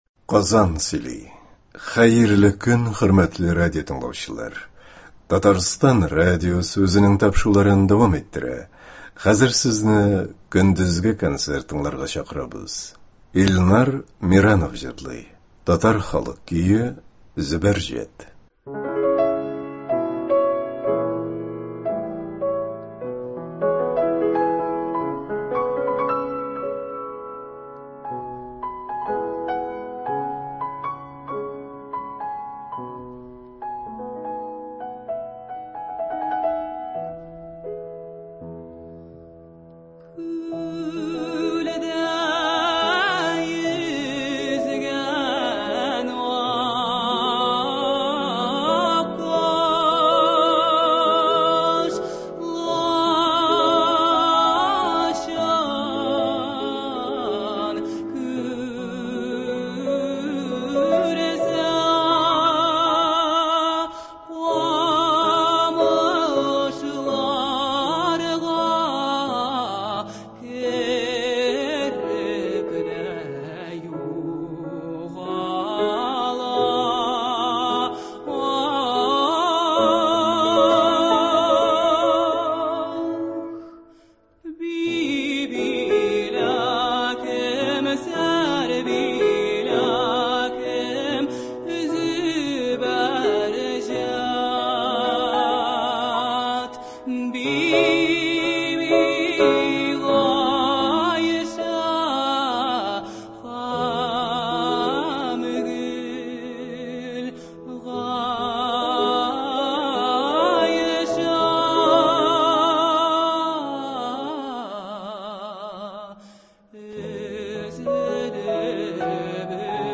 Драма артистлары концерты.